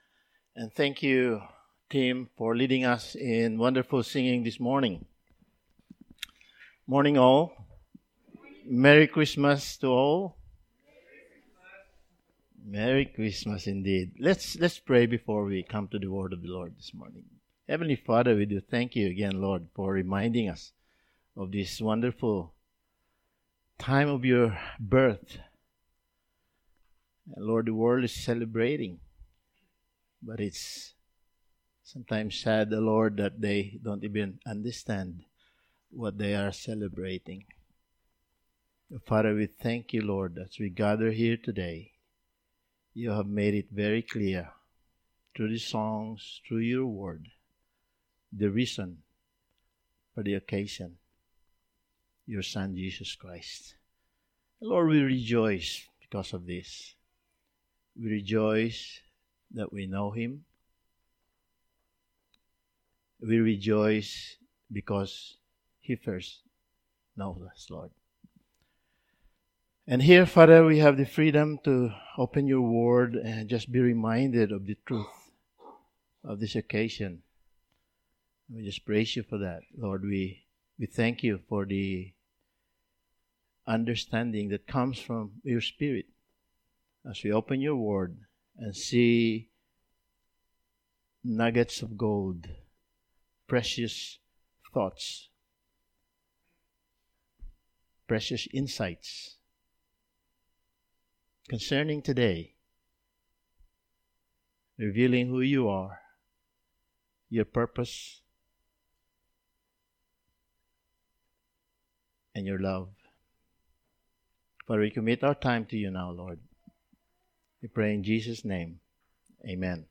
Topical Sermon Passage: Matthew 21:33-46